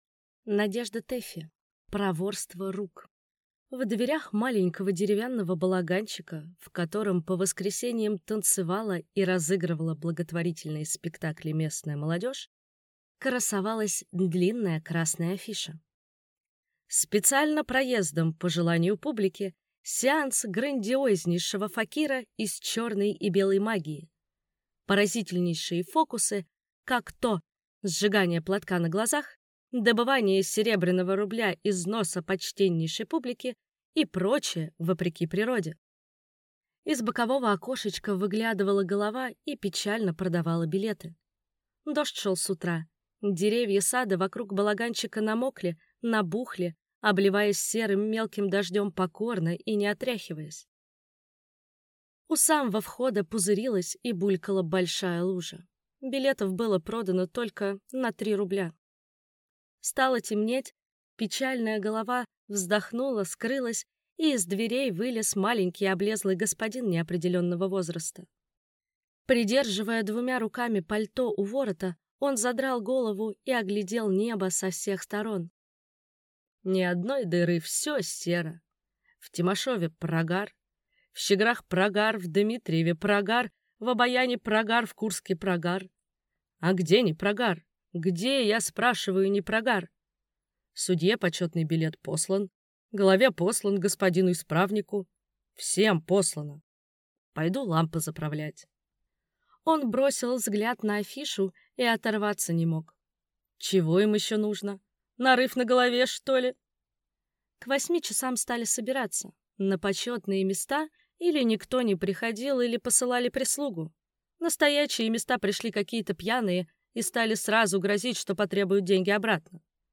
Аудиокнига Проворство рук | Библиотека аудиокниг